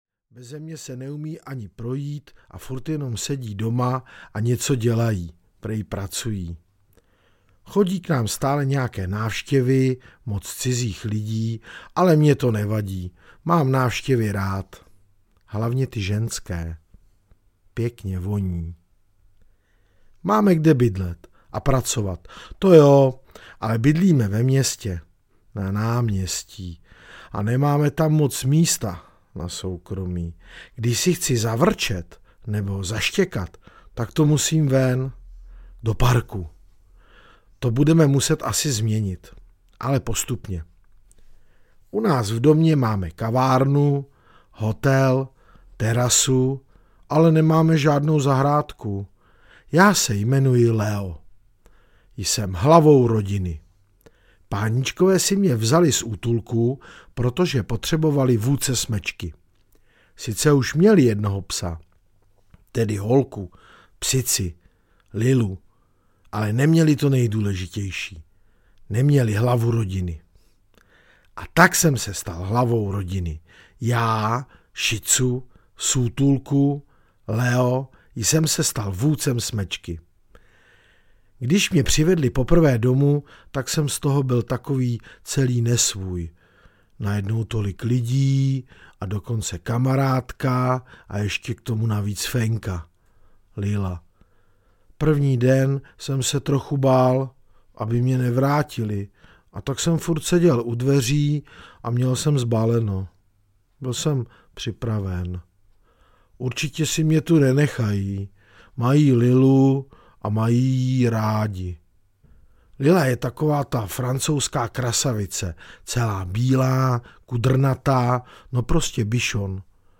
Pohádky z Piešťan a Vrzavky audiokniha
Ukázka z knihy